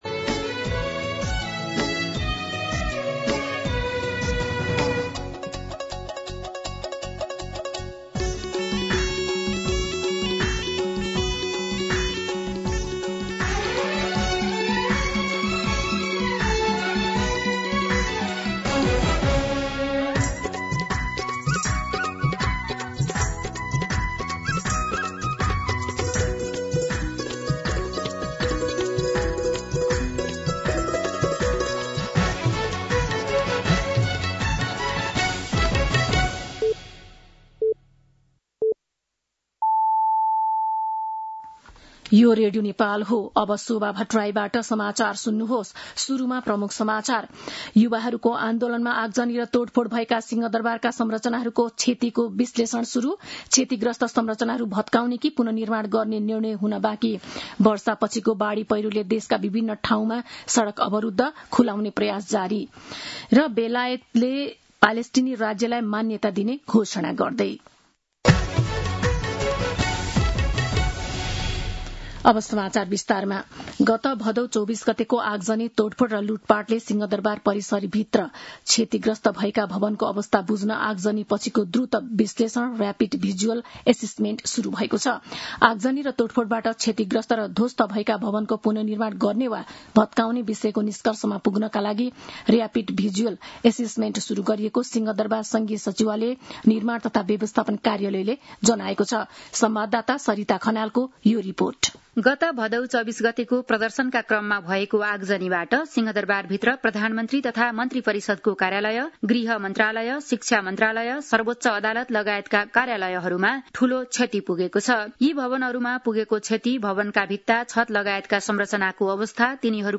दिउँसो ३ बजेको नेपाली समाचार : ५ असोज , २०८२